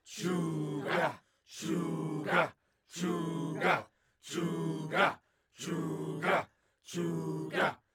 Human Crowd Chanting Tribal Slow